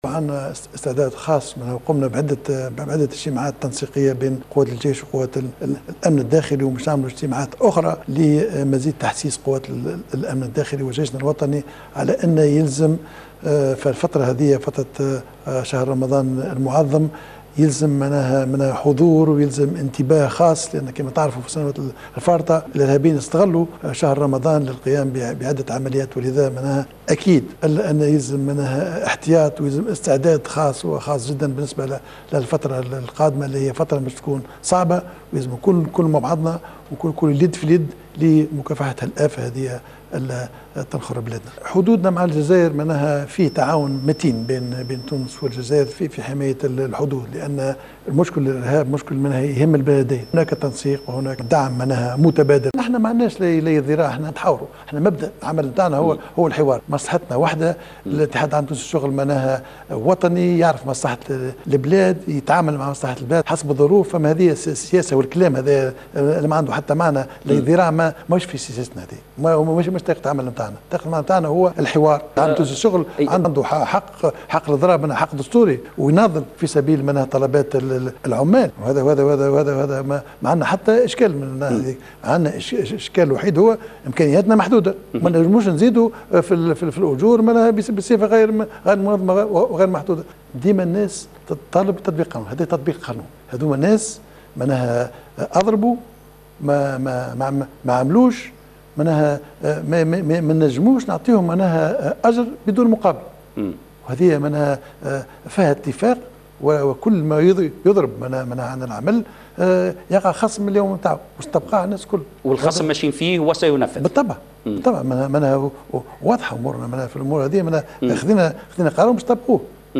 أكد رئيس الحكومة الحبيب الصيد في حواره على الوطنية الاولى على ضرورة اتخاذ مزيد من الاحتياطات الأمنية بالنسبة لشهر رمضان تحسبا لأي عمليات ارهابية ممكنة.